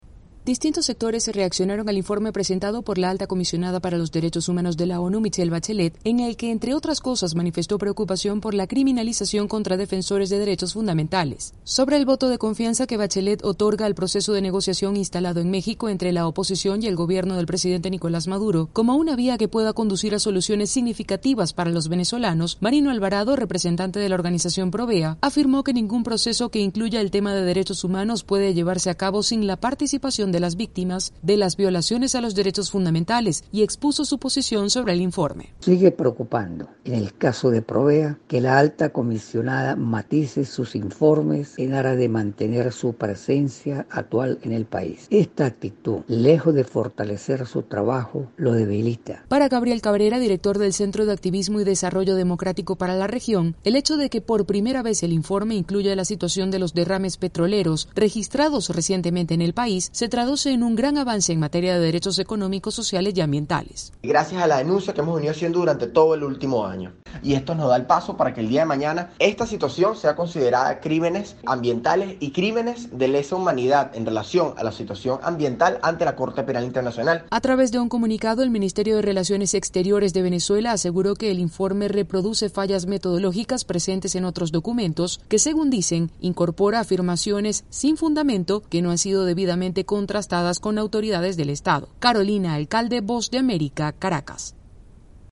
AudioNoticias